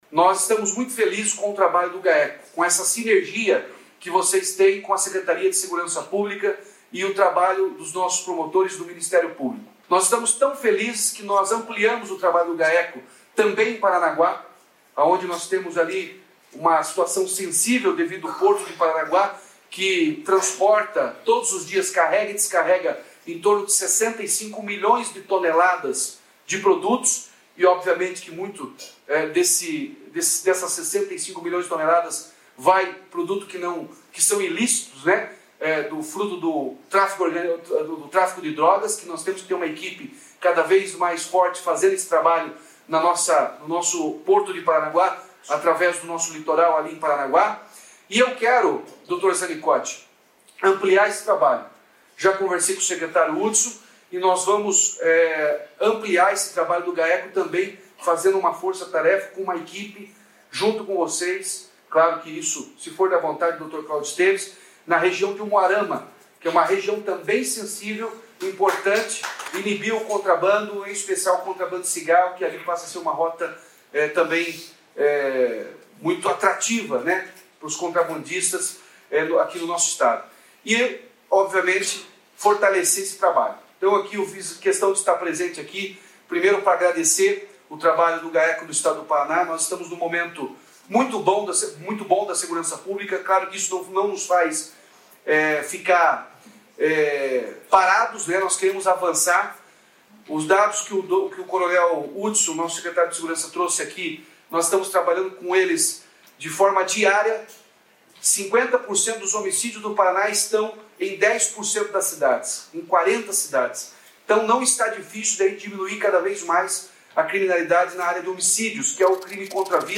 Sonora do governador Ratinho Junior sobre o apoio do Estado ao Gaeco para criação de núcleo em Umuarama